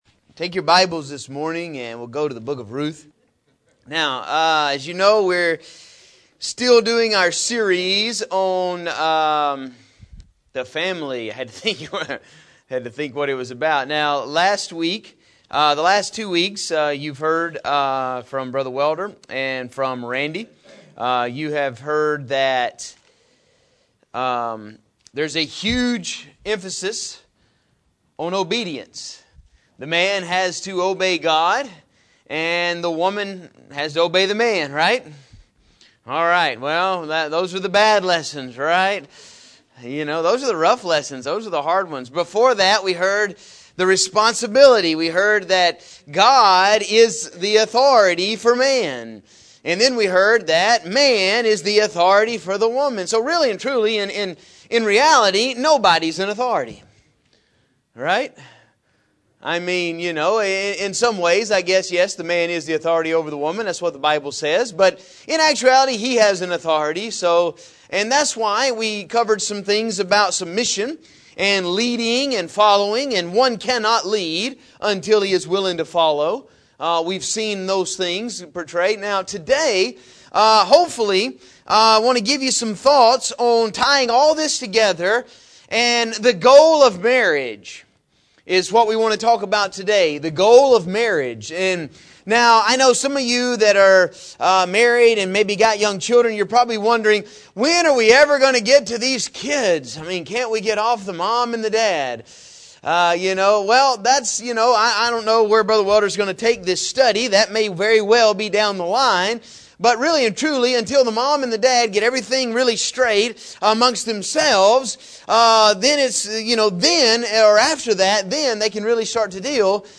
In this lesson we are going to look at some ways that you can have peace with you marriage.